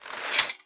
holster1.wav